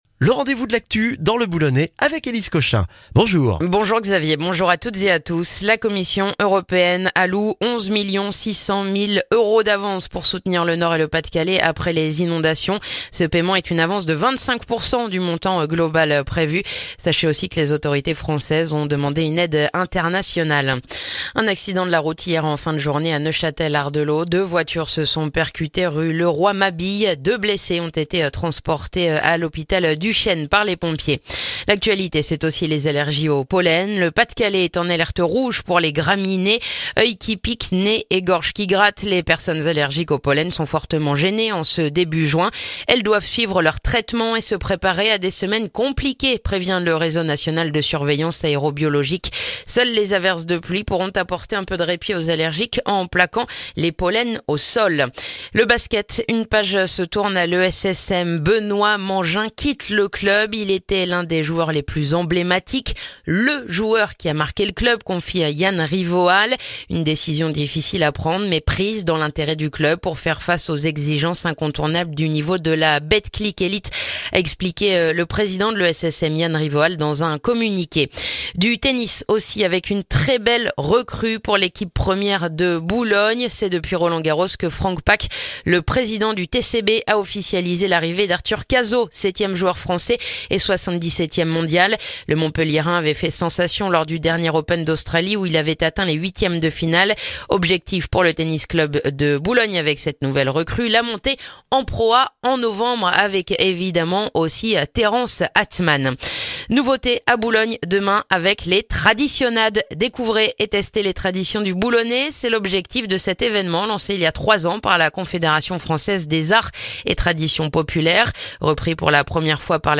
Le journal du vendredi 7 juin dans le boulonnais